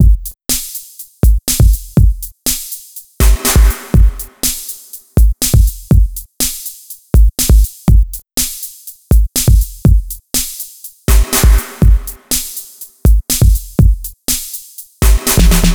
TSNRG2 Breakbeat 010.wav